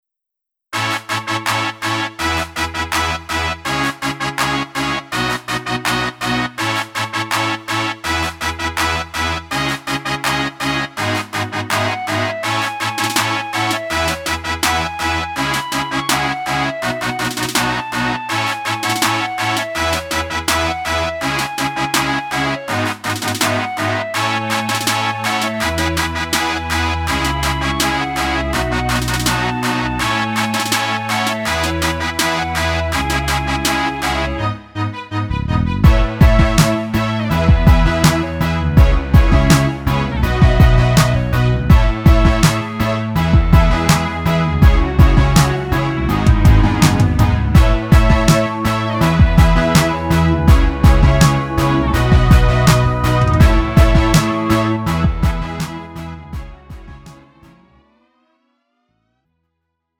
음정 -1키 3:11
장르 pop 구분 Lite MR